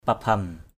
/pa-bʱʌn/ (d.) làng Vụ Bổn = village de Vu-bon.